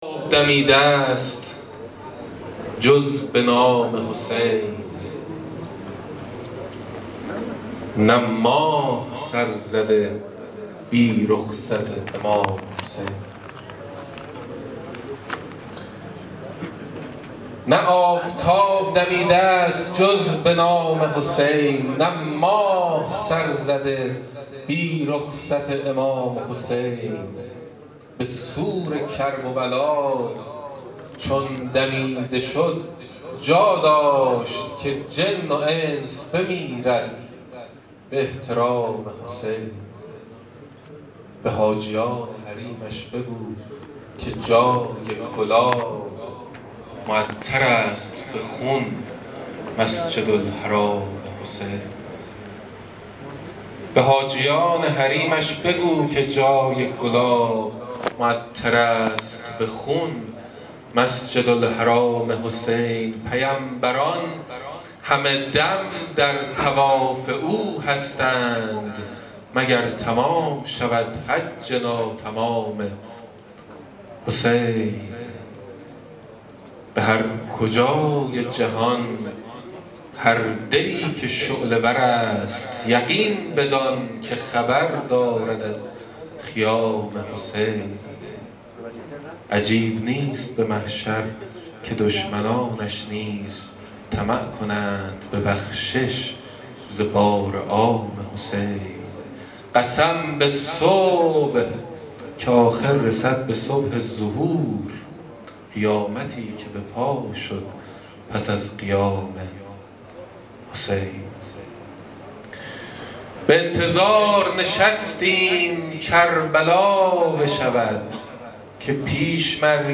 شعرخوانی میلاد عرفان‌پور در همایش «فعالان دانشجویی اربعین» صوت - تسنیم
میلاد عرفان پور در نخستین همایش «فعالان دانشجویی اربعین » اشعاری را با حال و هوایی حسینی برای میهمانان قرائت کرد.